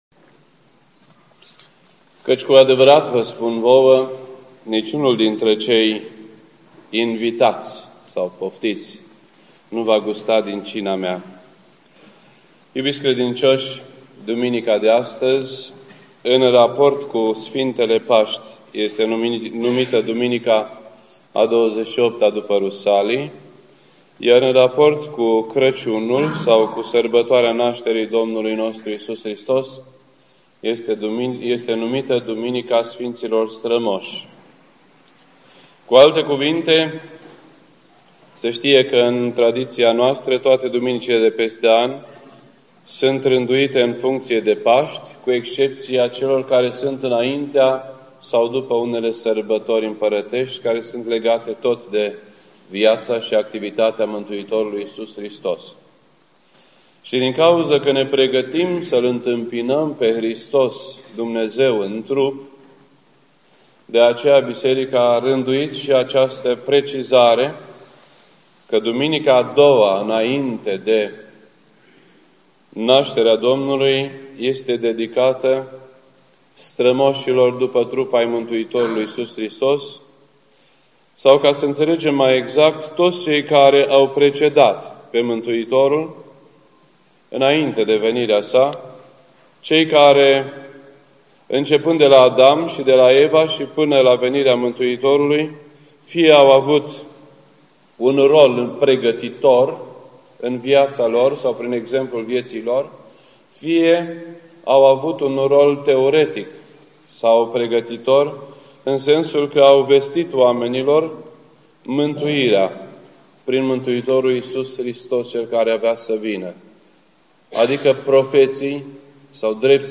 This entry was posted on Sunday, December 11th, 2011 at 8:51 PM and is filed under Predici ortodoxe in format audio.